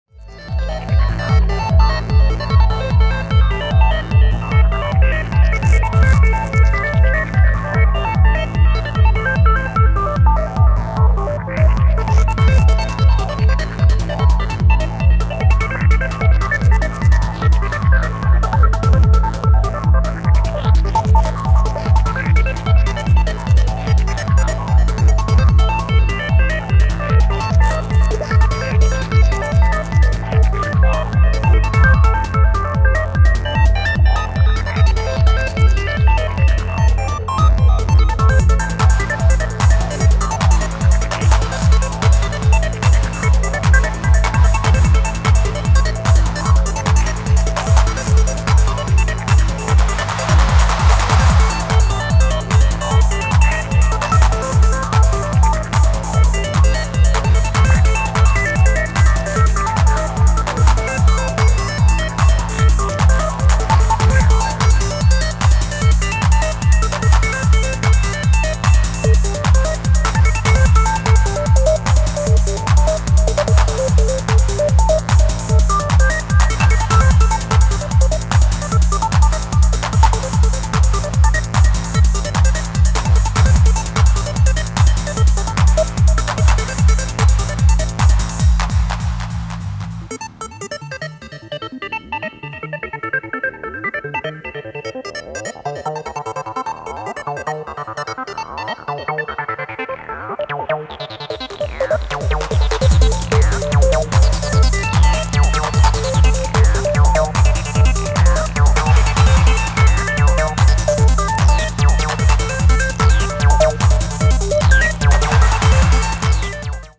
150BPMハイスピードトランス